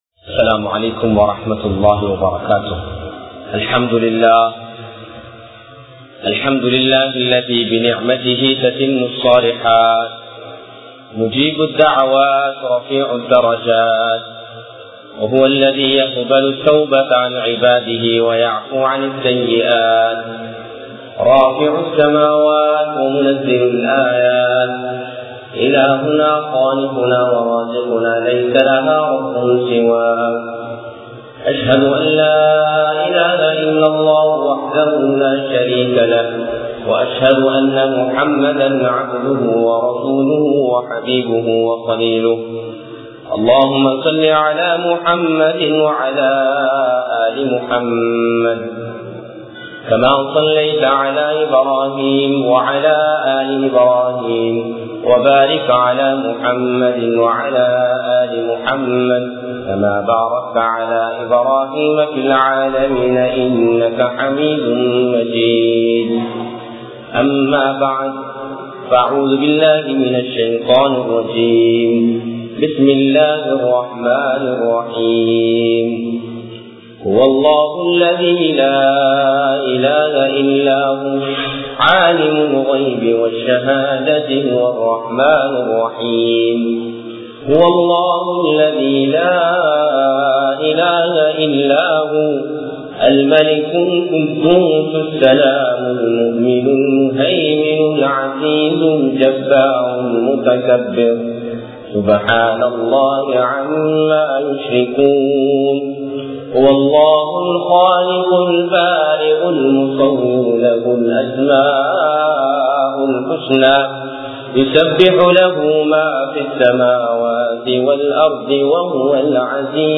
Allahvin Nimathukkal!(அல்லாஹ்வின் நிஃமத்துக்கள்!) | Audio Bayans | All Ceylon Muslim Youth Community | Addalaichenai
Galle,Gintota, Hussain Jumua Masjith